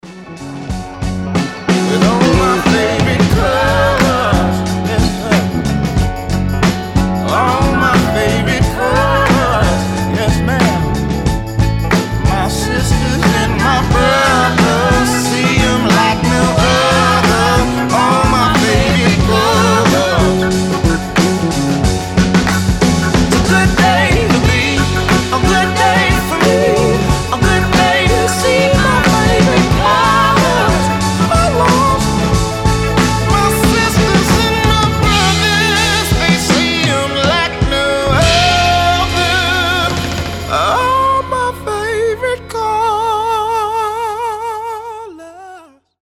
• Качество: 320, Stereo
душевные
RnB
блюз
соул